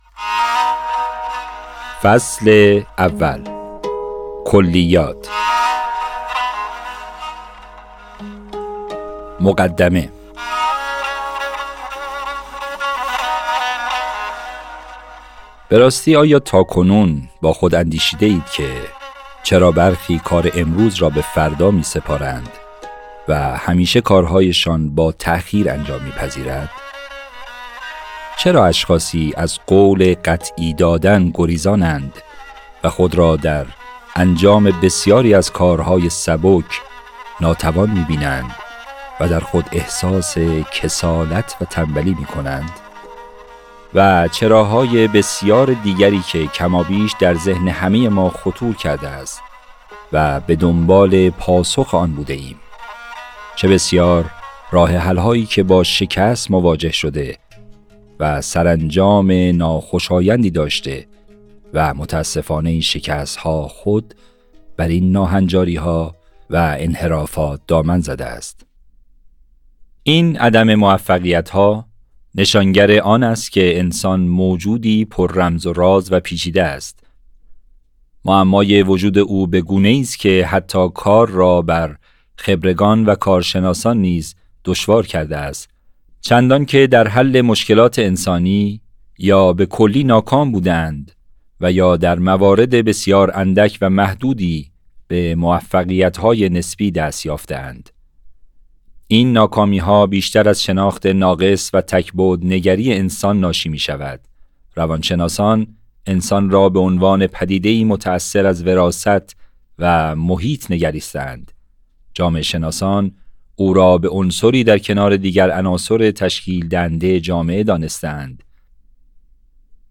یازدهمین کتاب صوتی تولید شده در آوایم به سفارش انتشارات مؤسسه آموزشی و پژوهشی امام خمینی ره، کتاب اهمال کاری است.